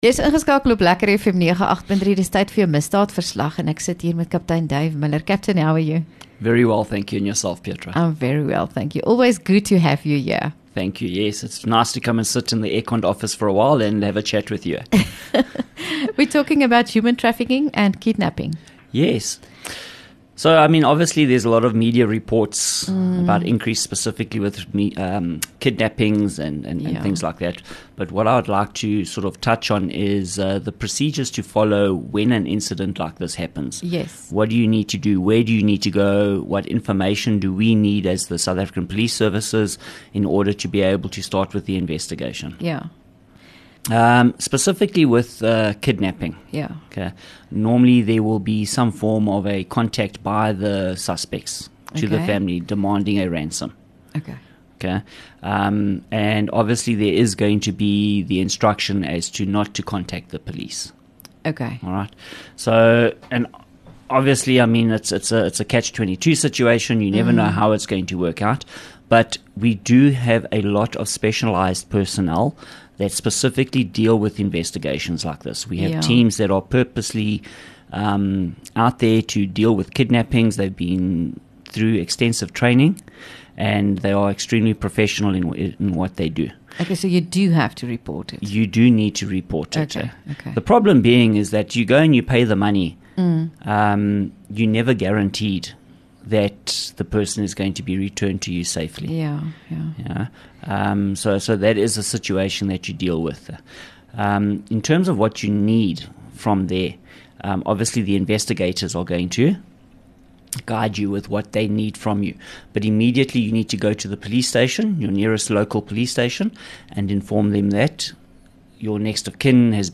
LEKKER FM | Onderhoude 26 Mar Misdaadverslag